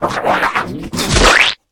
spit.ogg